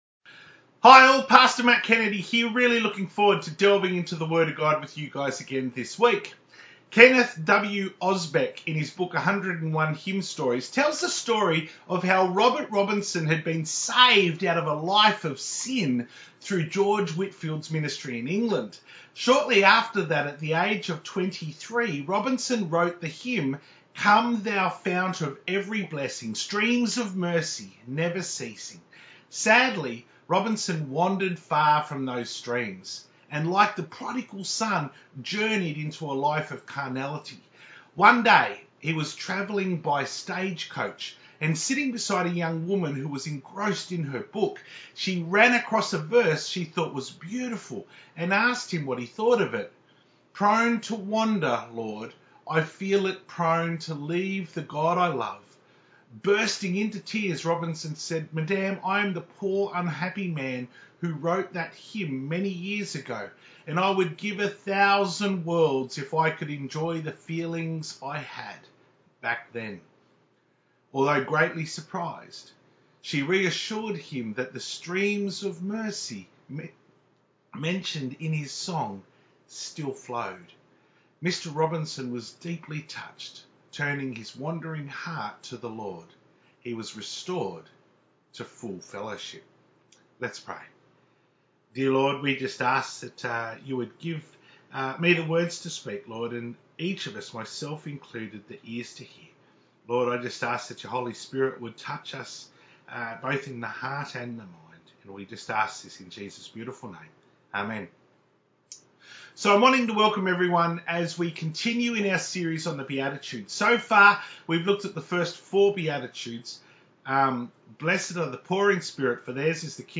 To view the Full Service from 7th March 2021 on YouTube, click here.